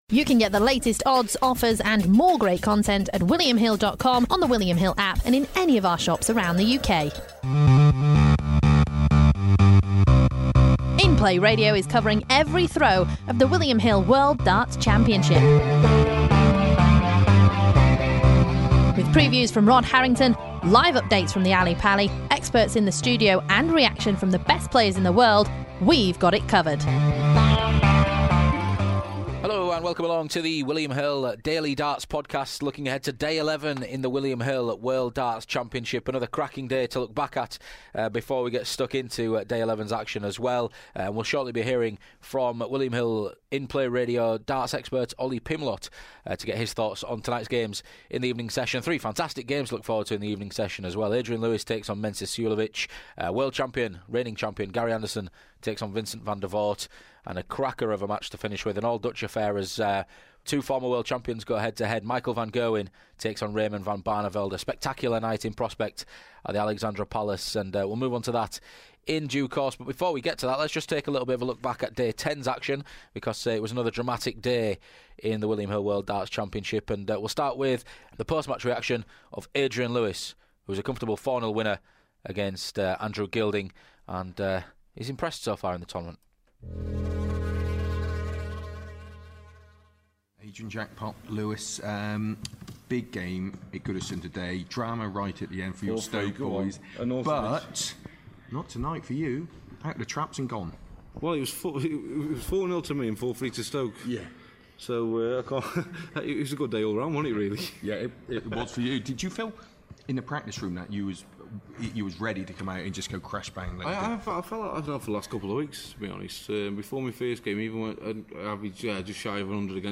We get the reaction of those three players to kick off this edition of the podcast before turning our attention to Tuesday's evening session.